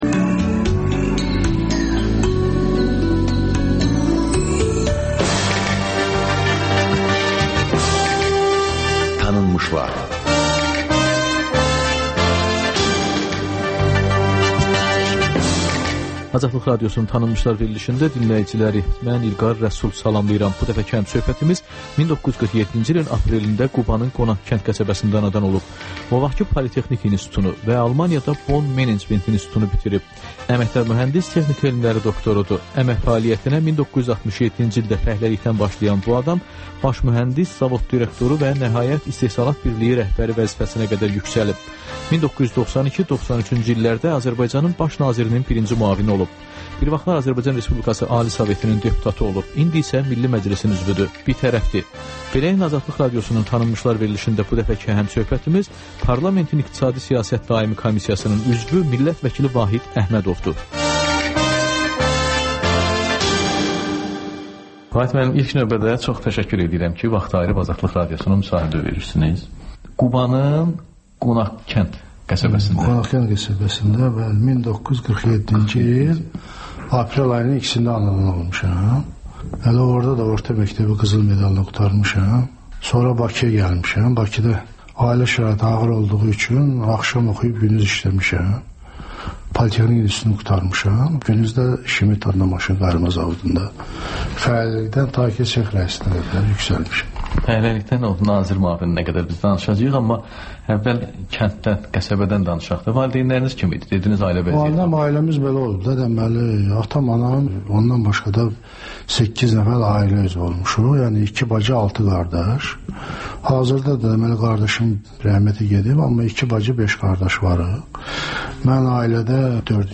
Ölkənin tanınmış simalarıyla söhbət (Təkrar)